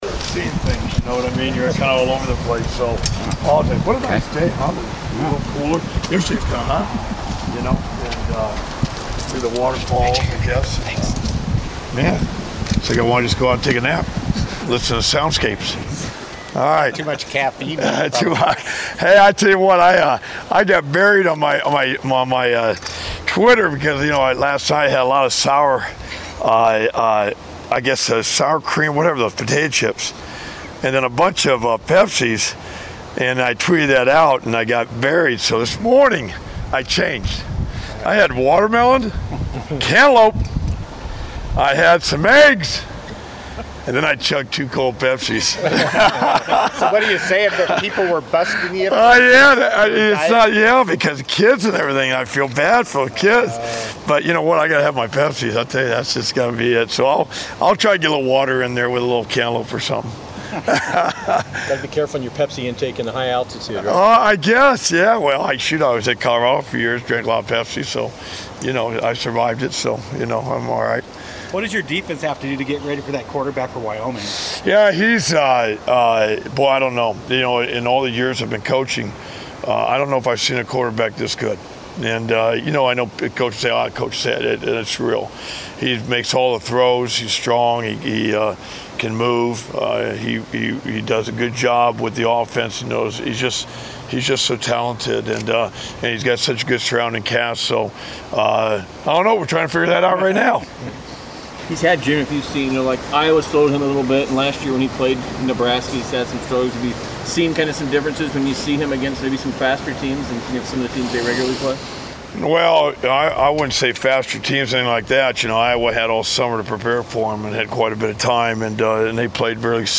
Jim Leavitt Media Session 9-13-17